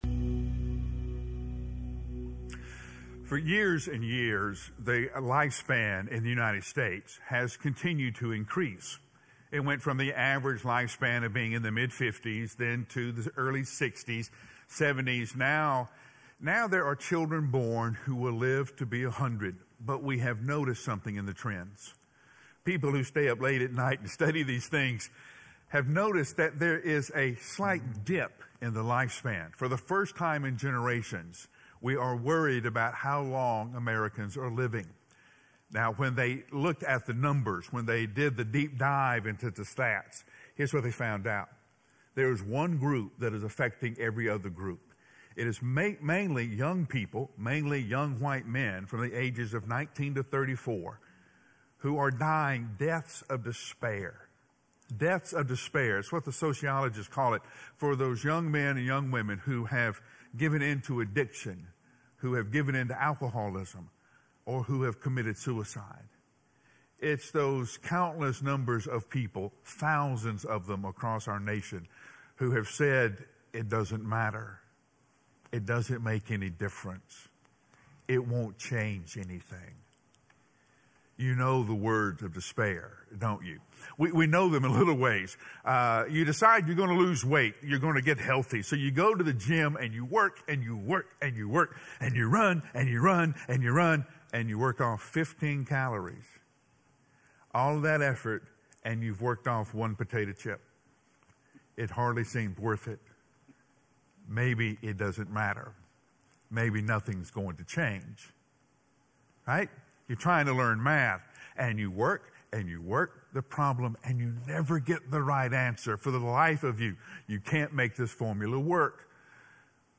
Why Have You Forsaken Me? - Sermon - Woodbine